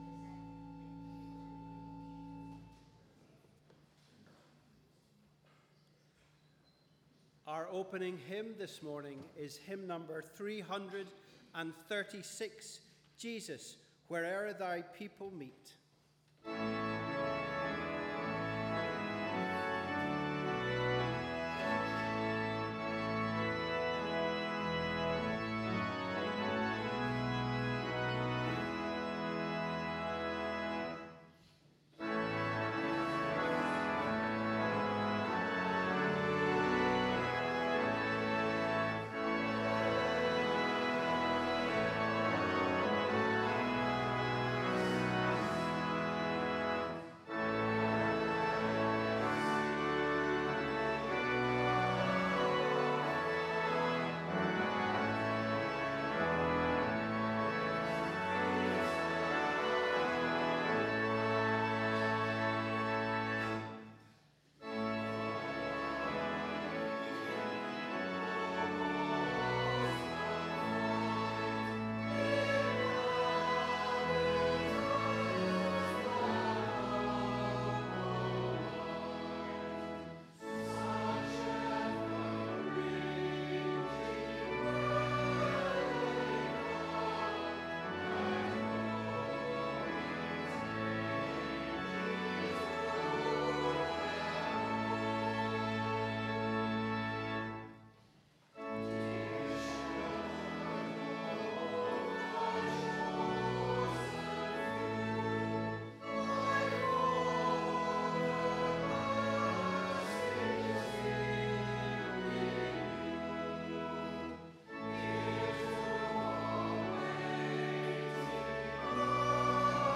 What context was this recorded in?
Wherever you are, we warmly welcome you to our service of Holy Communion on the 7th Sunday after Trinity.